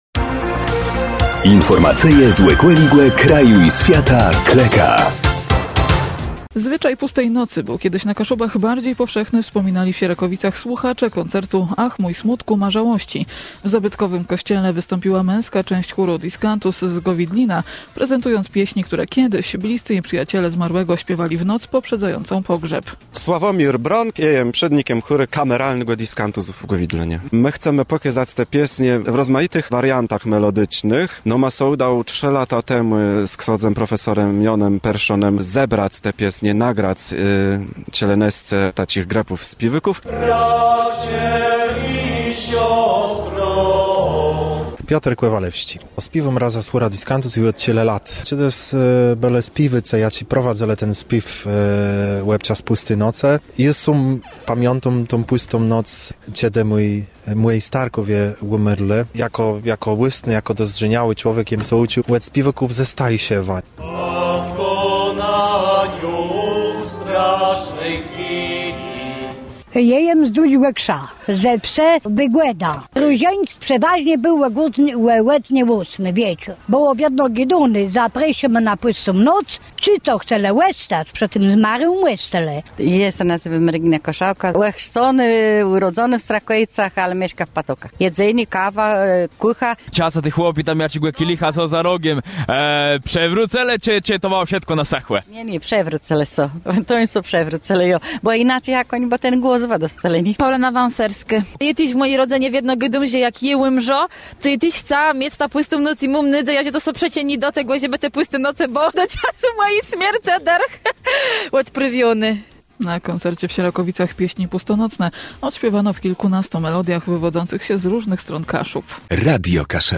W zabytkowym kościele wystąpiła męska część chóru Discantus z Gowidlina prezentując pieśni, które kiedyś bliscy i przyjaciele zmarłego śpiewali w noc poprzedzającą pogrzeb. Na koncercie w Sierakowicach pieśni pustonocne odśpiewano w kilkunastu melodiach, wywodzących się z różnych stron Kaszub.
03_o-pustej-nocy-koncert.mp3